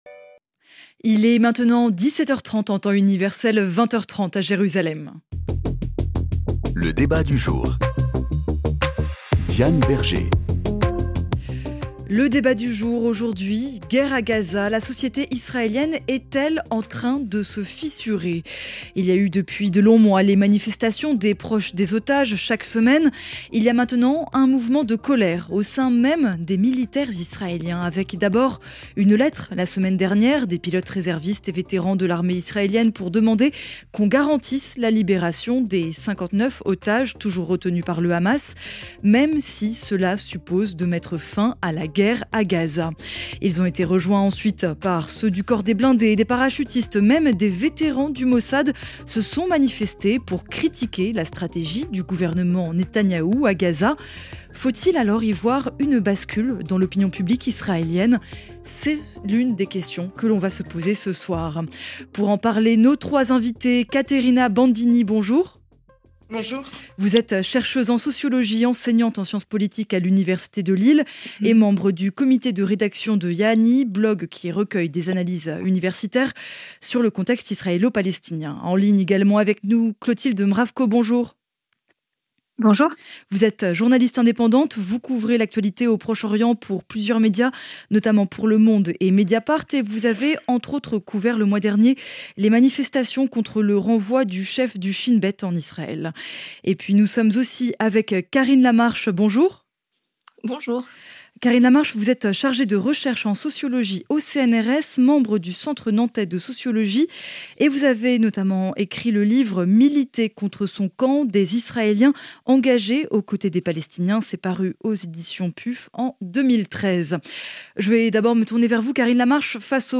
Elon Musk est-il en train de devenir le boulet de Donald Trump ? Sur quoi cette collaboration peut-elle déboucher ?Pour en débattre